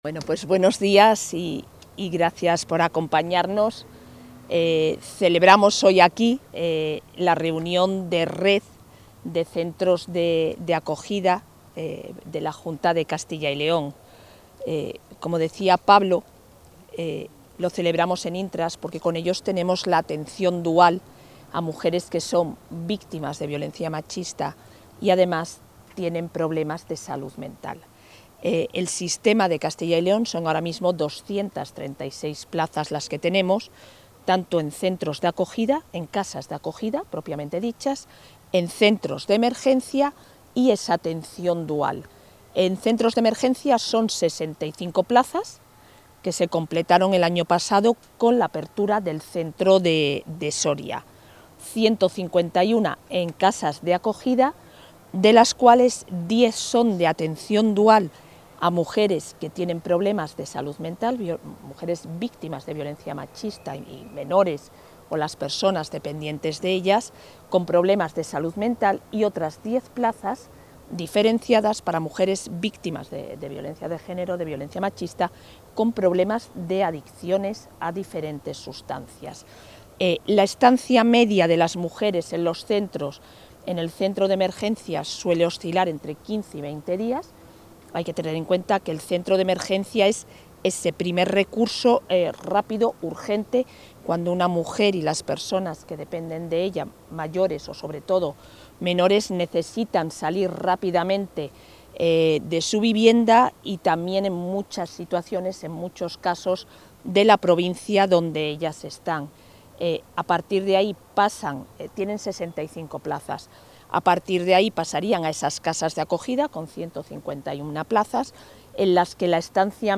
Declaraciones de la vicepresidenta de la Junta.